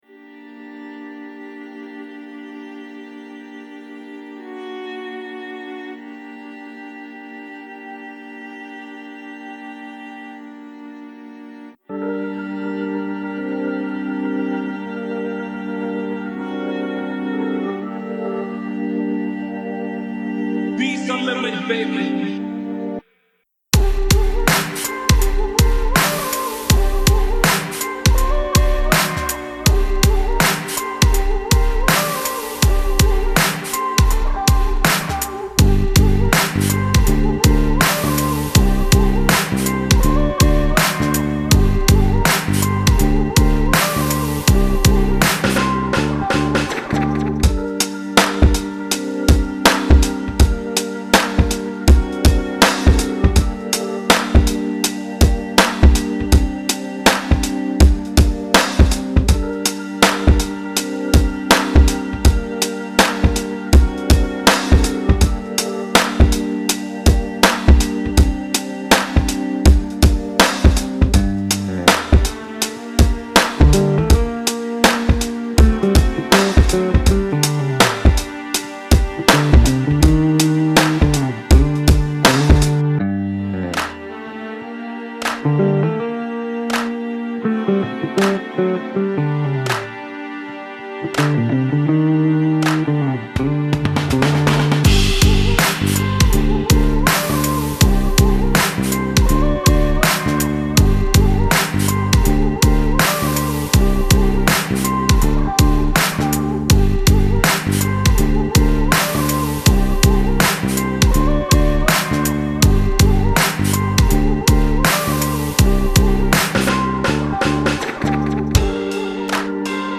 Late-night R&B beats for independent artists.